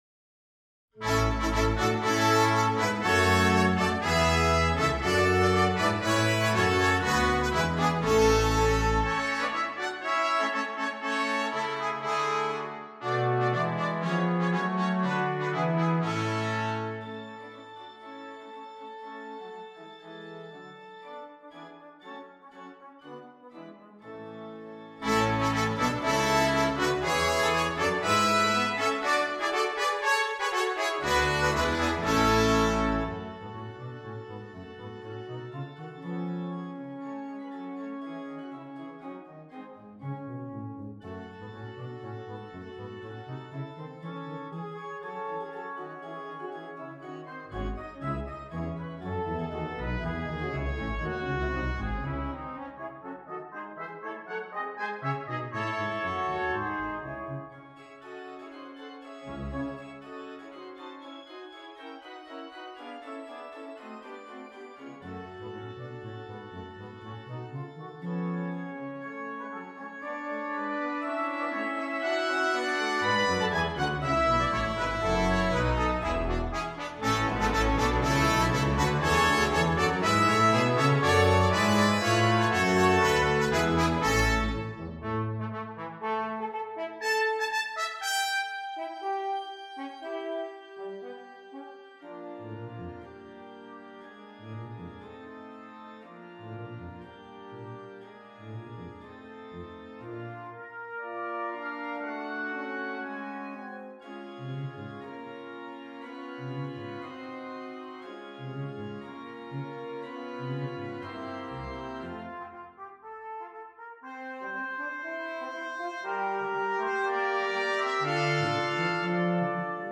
Brass Quintet and Organ
brilliant fanfare piece